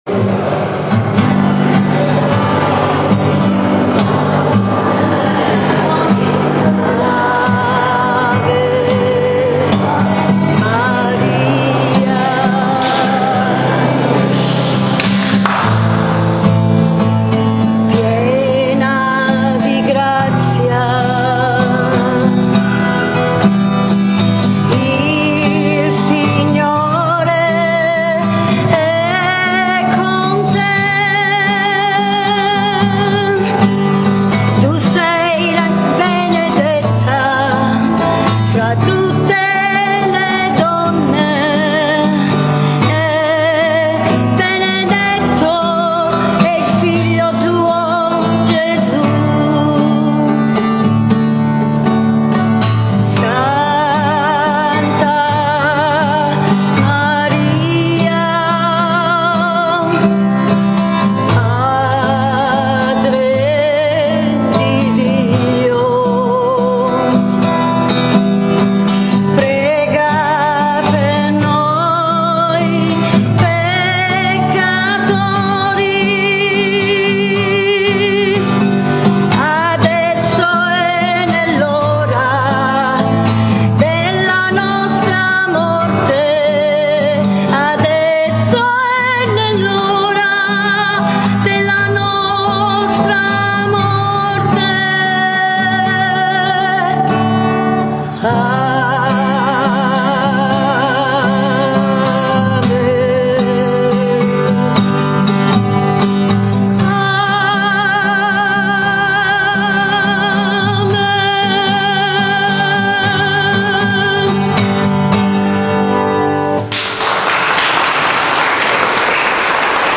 ECCO L'AUDIO DELL'INCONTRO DI DOMENICA 26 maggio 2013 al Santuario della Madonna delle Milizie di Donnalucata